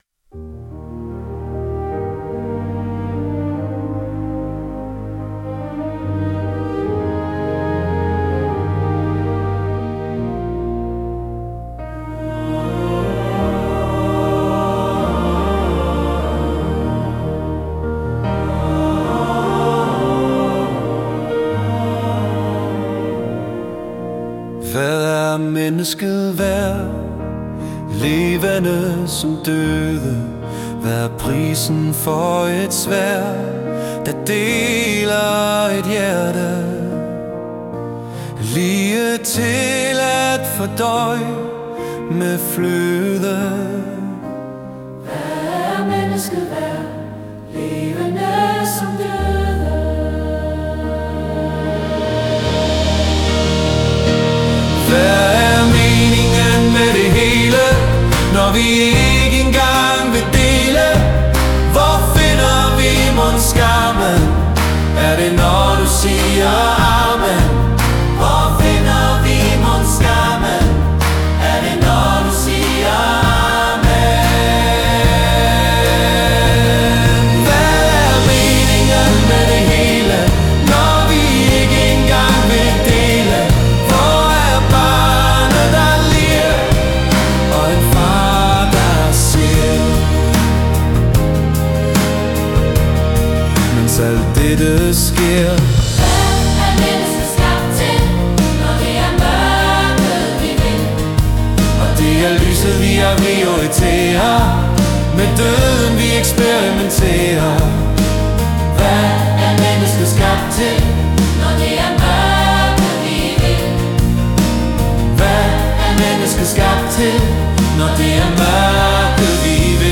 Er det en salme… moderne… Klik på teksten for at høre en sang version af teksten.